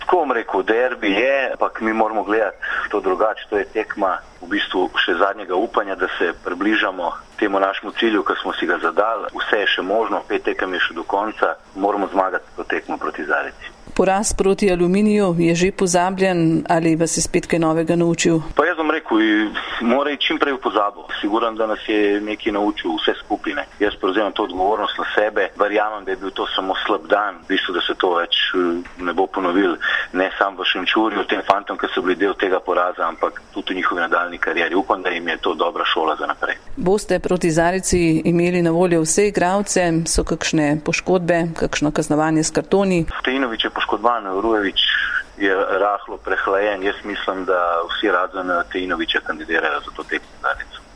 v pogovoru